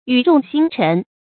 語重心沉 注音： ㄧㄩˇ ㄓㄨㄙˋ ㄒㄧㄣ ㄔㄣˊ 讀音讀法： 意思解釋： 言辭懇切，心情沉重。